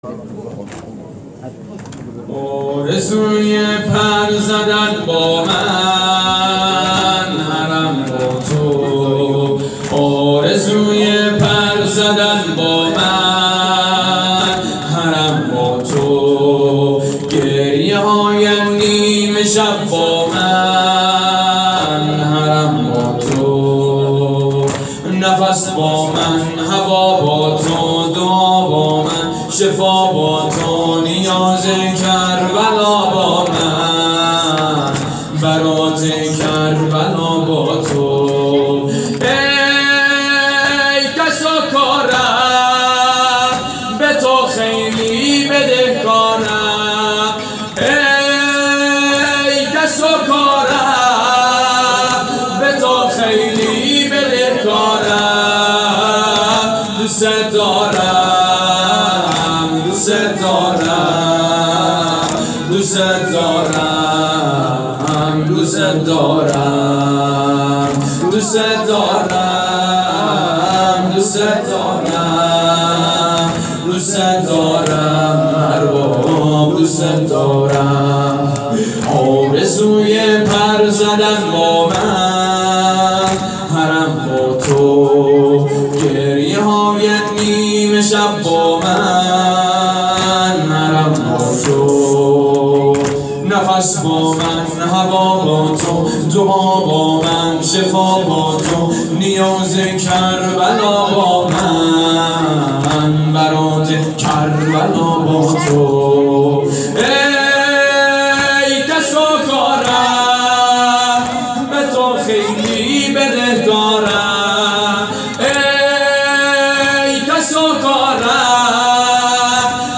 زمینه شب هشتم محرم 98 آرزوی پر زدن با من(حسینیه ریحانة الحسین